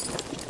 tac_gear_33.ogg